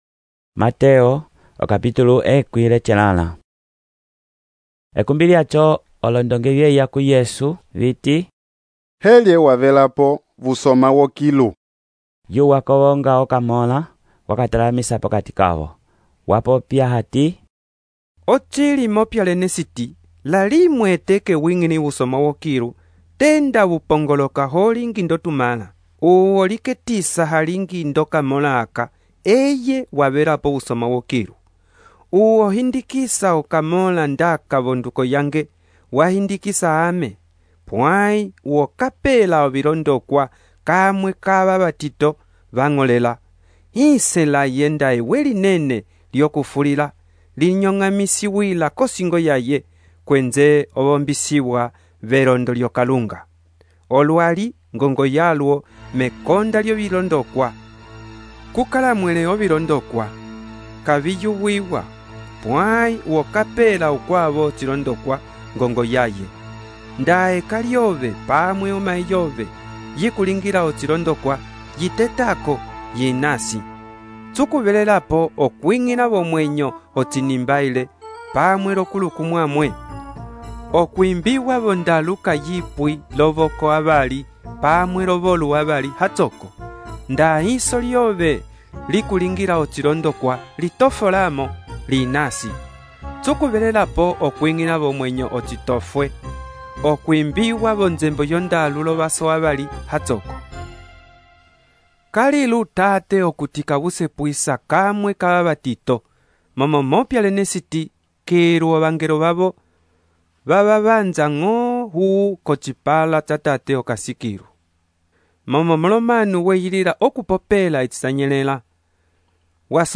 texto e narração , Mateus, capítulo 18